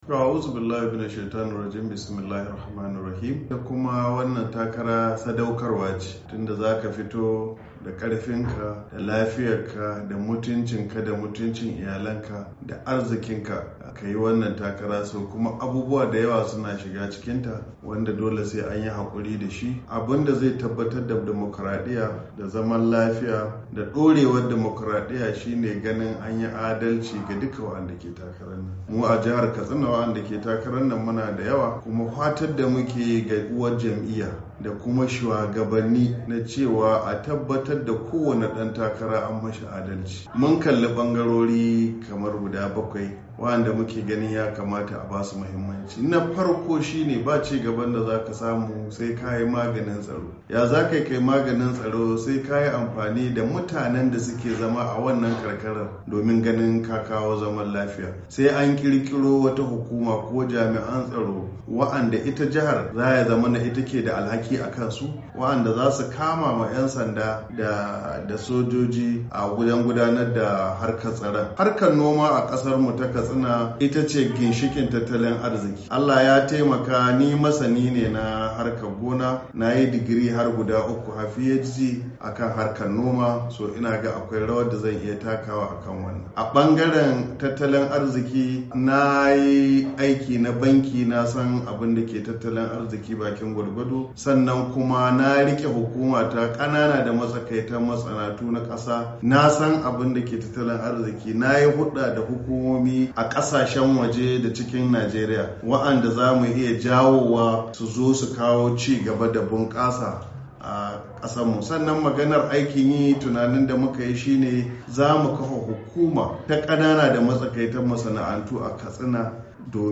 Dakta Radda ya bayyana hakan ne a wata hira ta musamman da muryar Amurka a birnin tarayyar kasar Abuja a daidai lokacin da ake tunkarar zaben fidda gwanin jam’iyyun siyasar Najeriya ciki har da jam’iyyar APC mai mulki da babbar jam’iyyar adawa ta PDP a Najeriya,.